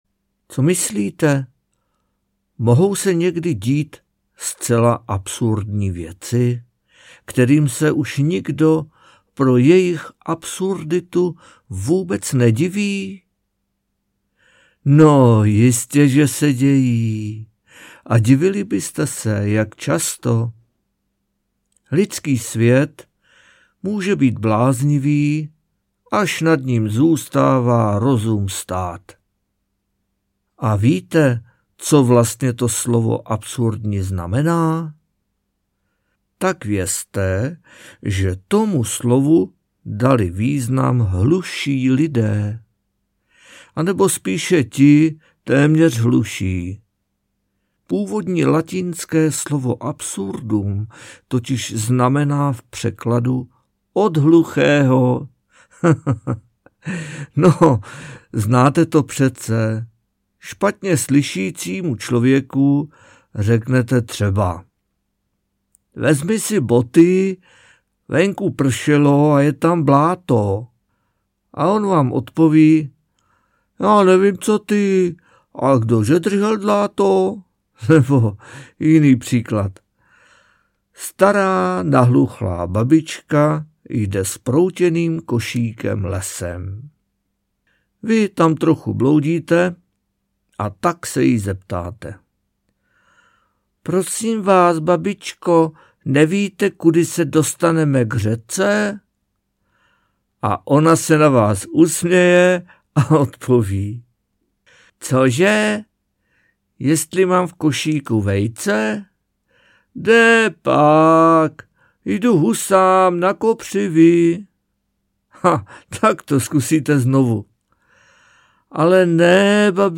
Audioknihy